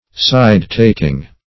Definition of side-taking.
Search Result for " side-taking" : The Collaborative International Dictionary of English v.0.48: Side-taking \Side"-tak`ing\, n. A taking sides, as with a party, sect, or faction.